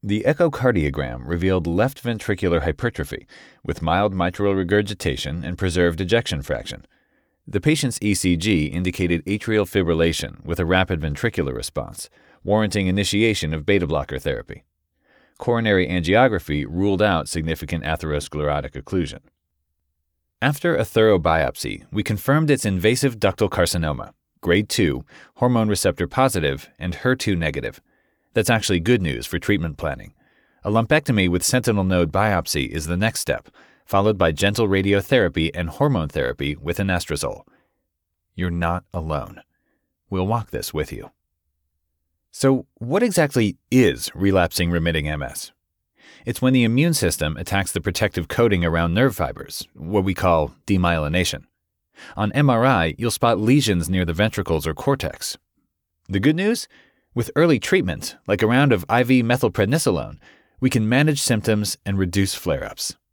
Medical Narration Demo - Educated Millennial Male - A Relatable Pro - Clear Diction
Professional home studio with Source Connect Standard. Sennheiser MKH-416 and Neumann TLM 103 microphones.